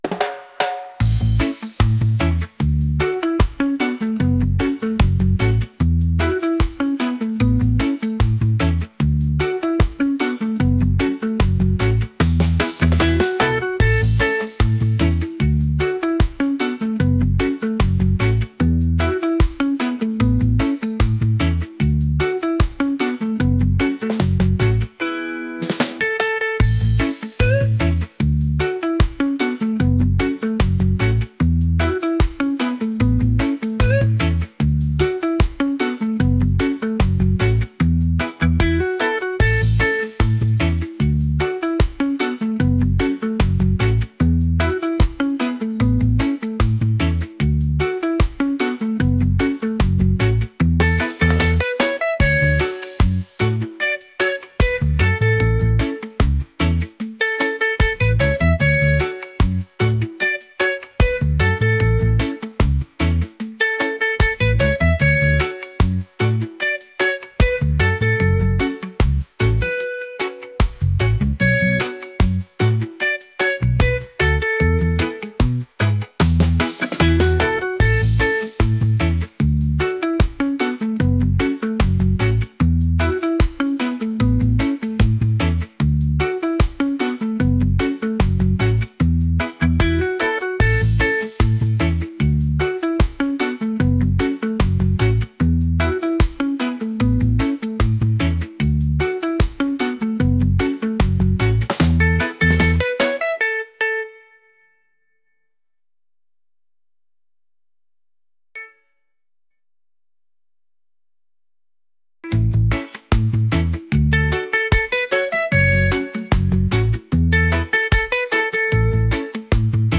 upbeat | reggae